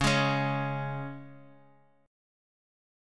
C#5 chord